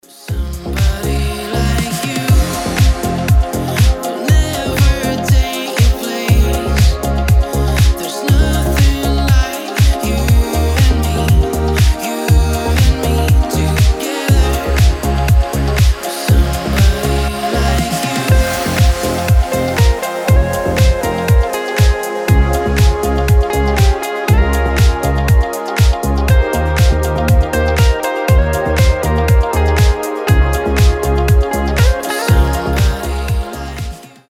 • Качество: 320, Stereo
deep house
мелодичные
спокойные